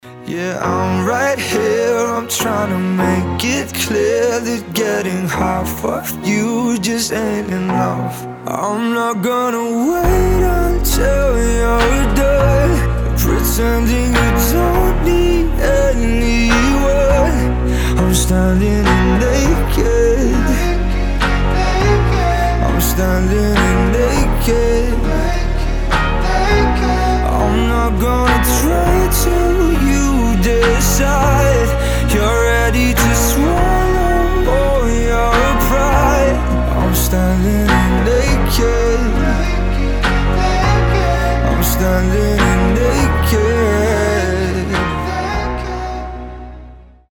• Качество: 320, Stereo
поп
мужской вокал
грустные
спокойные
пианино
ballads